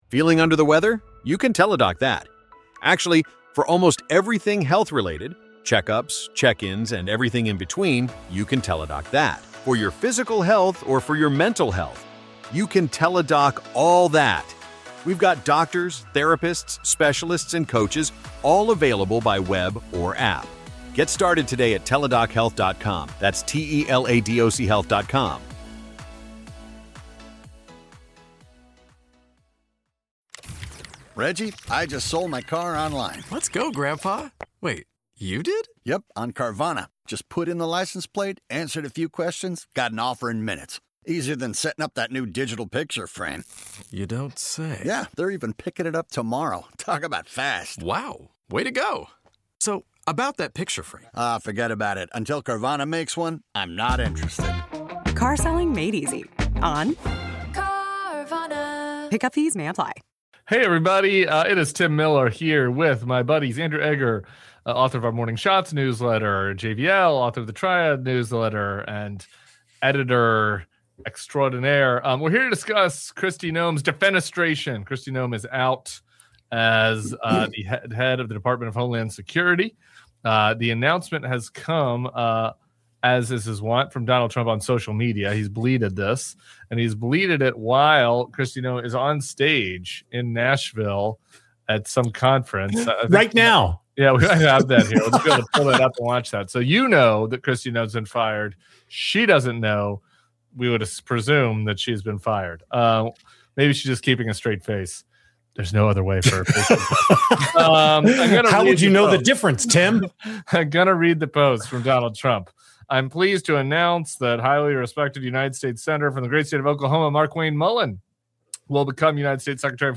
going live to cover Trump firing Kristi Noem.